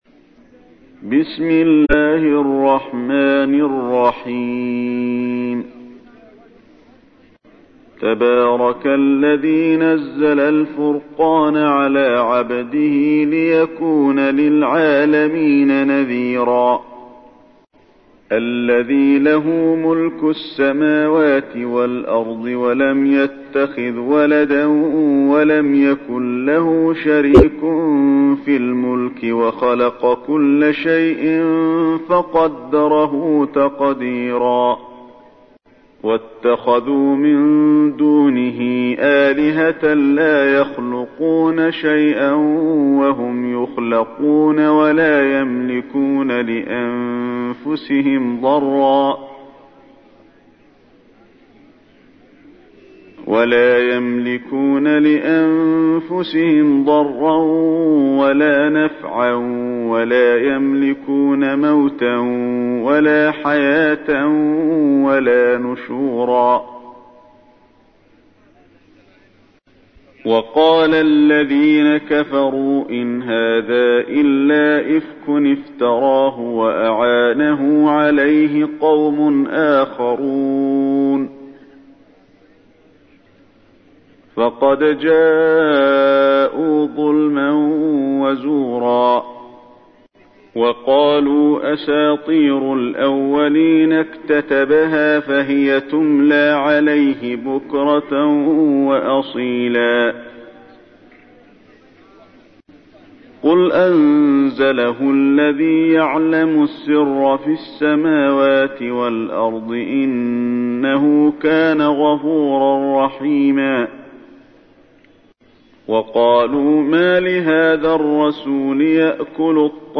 تحميل : 25. سورة الفرقان / القارئ علي الحذيفي / القرآن الكريم / موقع يا حسين